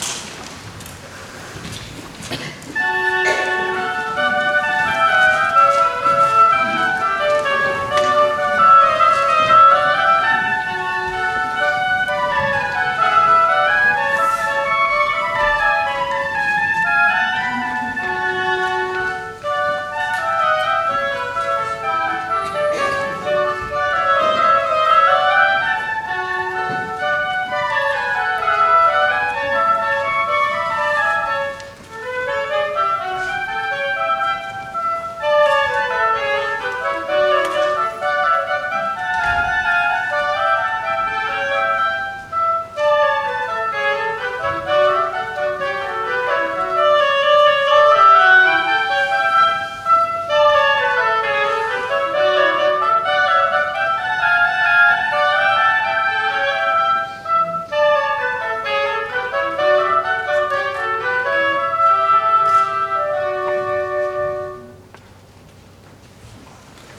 (2) Hymn (Beethoven): Joyful, Joyful, We Adore You
oboe